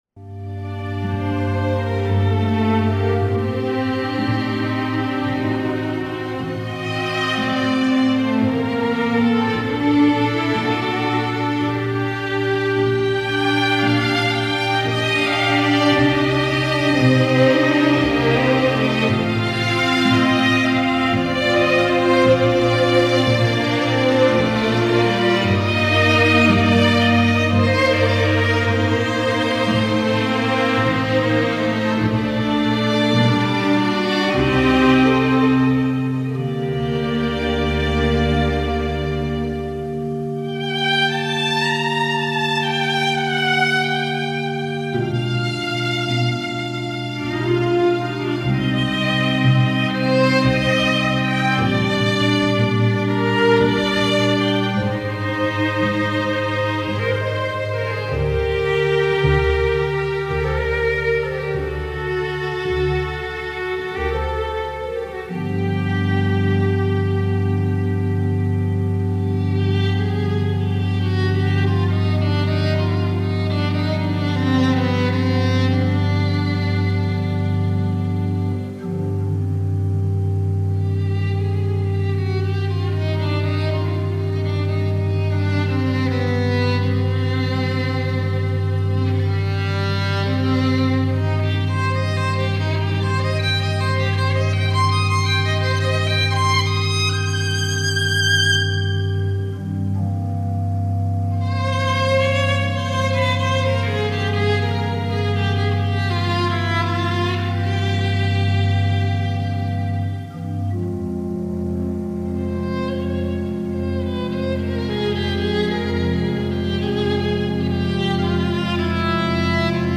Траурная_музыка_-_Возложение_цветов
Traurnaya_muzyka___Vozlozhenie_cvetov.mp3